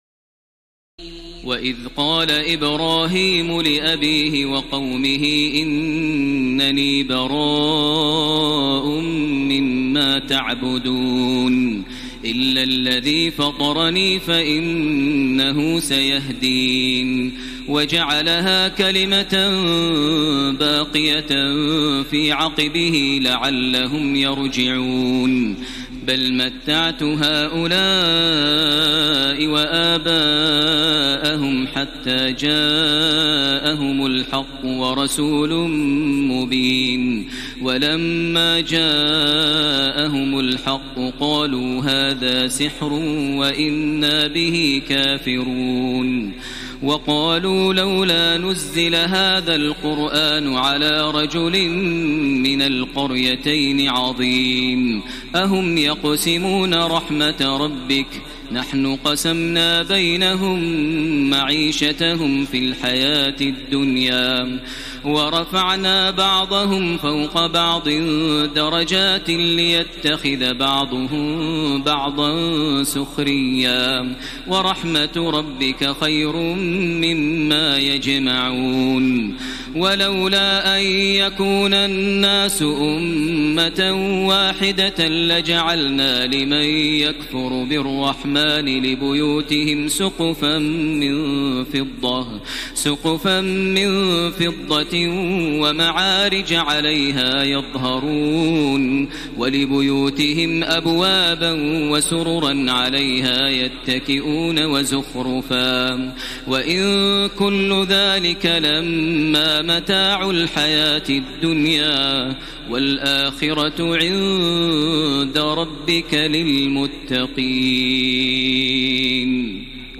تراويح ليلة 24 رمضان 1433هـ من سور الزخرف (26-89) والدخان و الجاثية Taraweeh 24 st night Ramadan 1433H from Surah Az-Zukhruf and Ad-Dukhaan and Al-Jaathiya > تراويح الحرم المكي عام 1433 🕋 > التراويح - تلاوات الحرمين